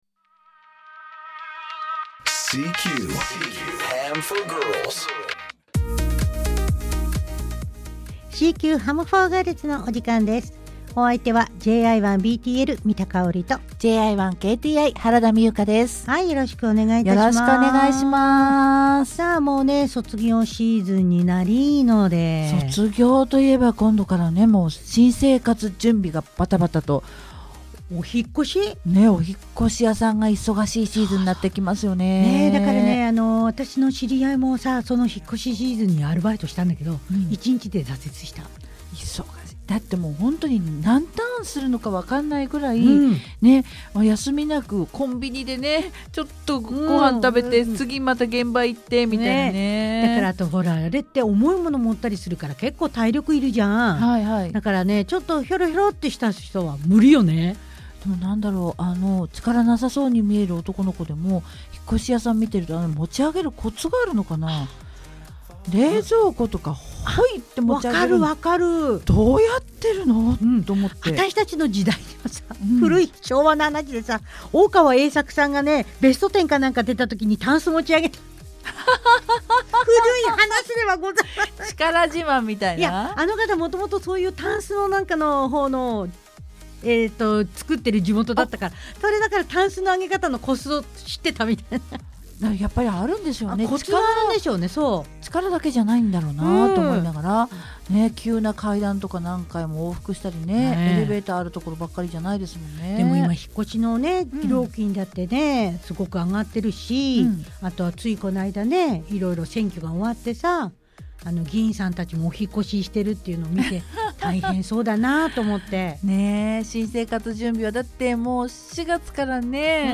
無線初心者の女子がお送りする、「CQ HAM FOR GIRLS」。